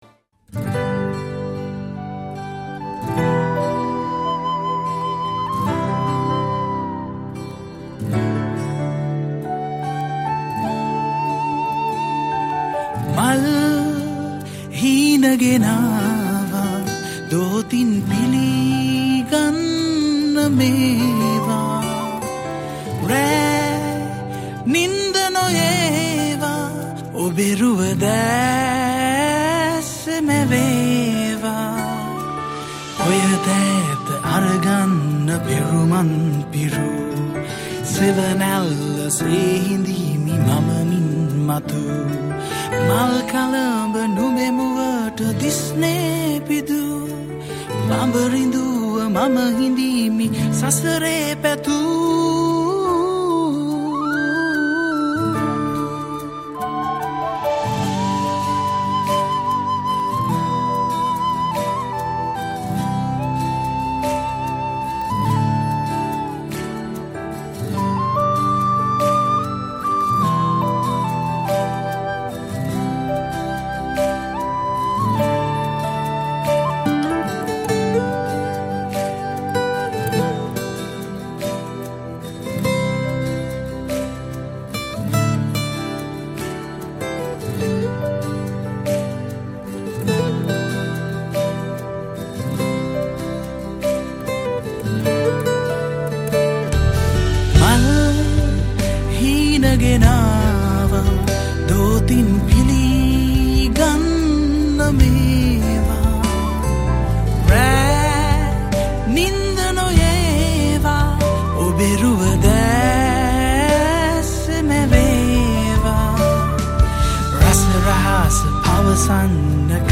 Keyboards and Guitars
Flutes